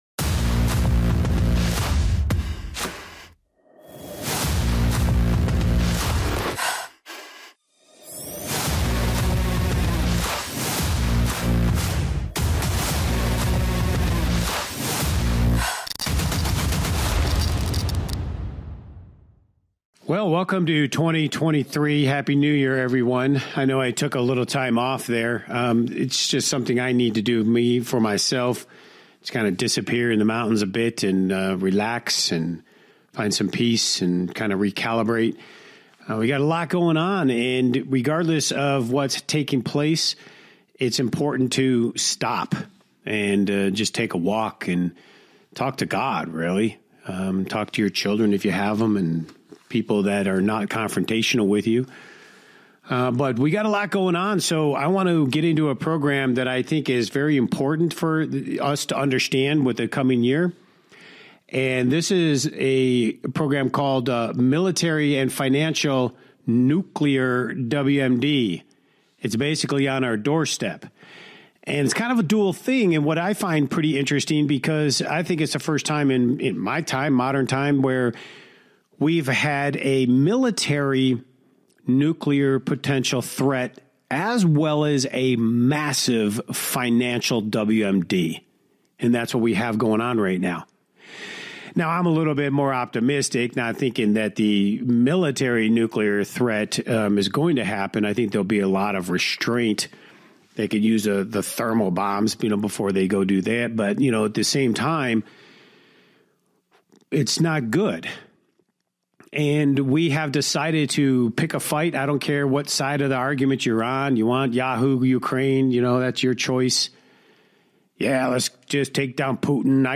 Rigged Against You Talk Show